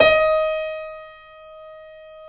Index of /90_sSampleCDs/Roland - Rhythm Section/KEY_YC7 Piano mf/KEY_mf YC7 Mono
KEY EB4 F 0G.wav